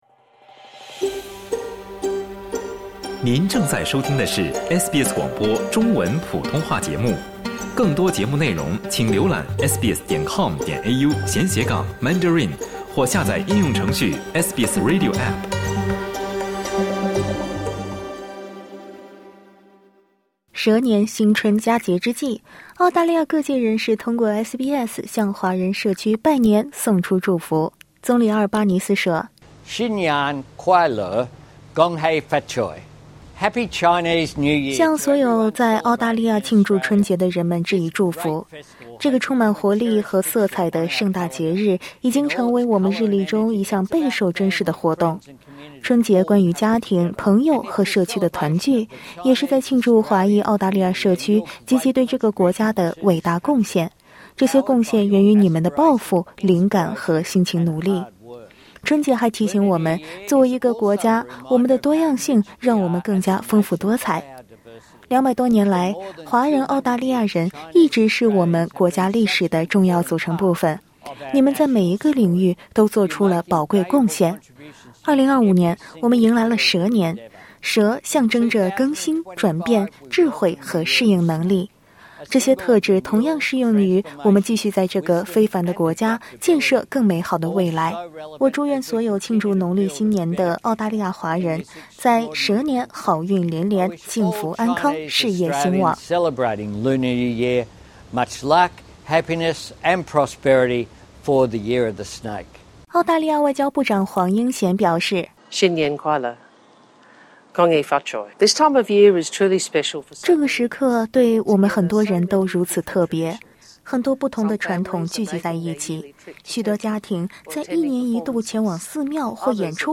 总理阿尔巴尼斯向华人送上新春祝福。